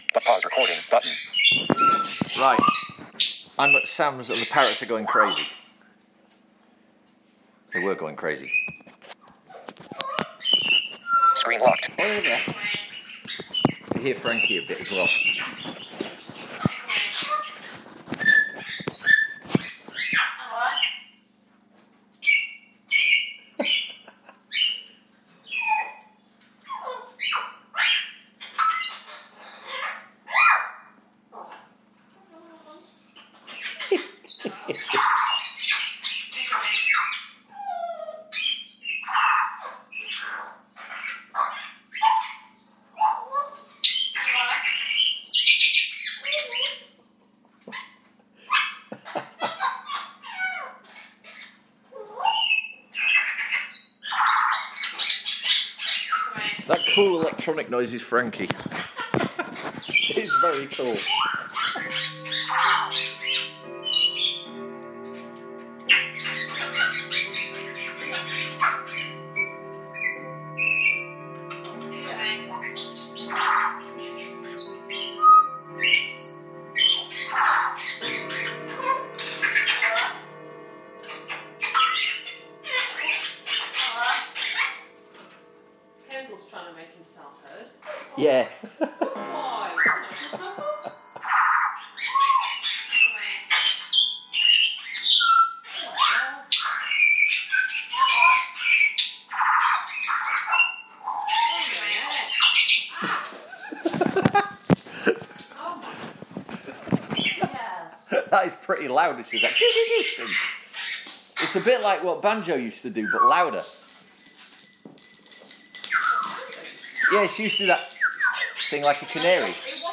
Seriously chatty parrots!